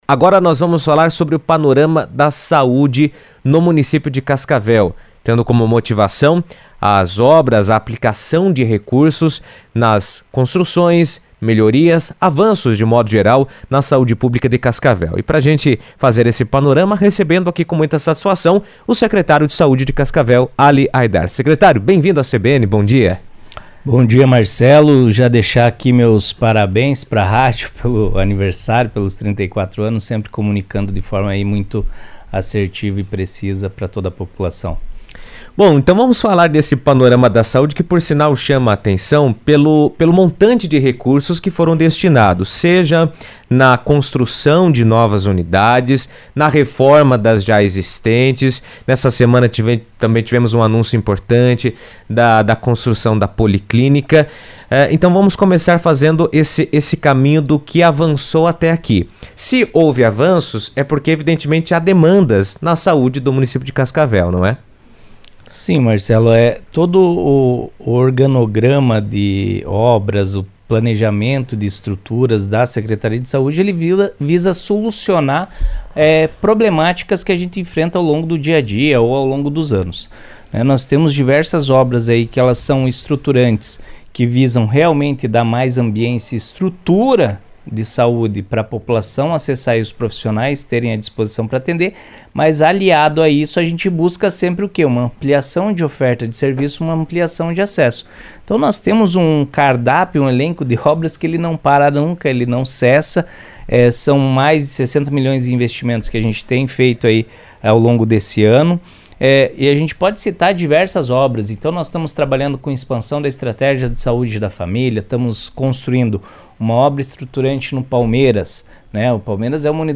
Cascavel investe R$ 60 milhões em obras e melhorias na rede de saúde, incluindo unidades de atendimento e equipamentos estratégicos. Na CBN, o secretário de Saúde, Ali Haidar, falou sobre os projetos em andamento e os benefícios esperados para a população.